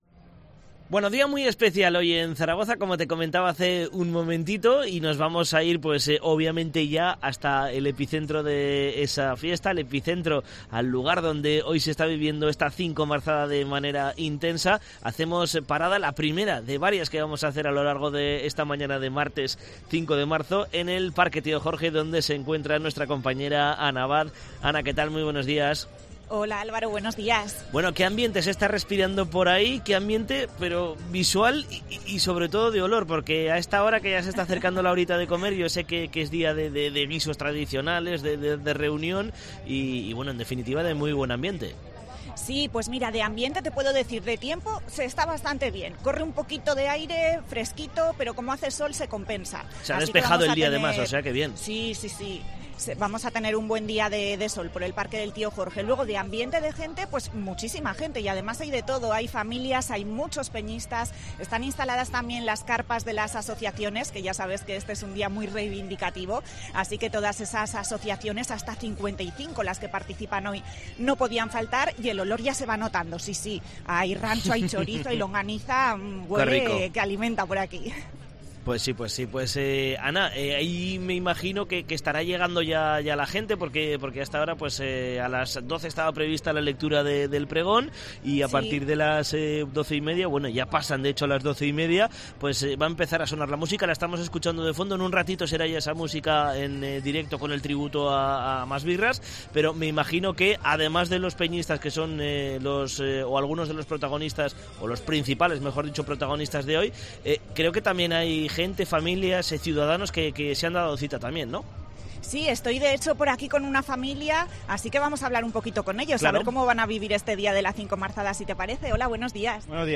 Visitamos el Parque del Tío Jorge durante la celebración de la Cincomarzada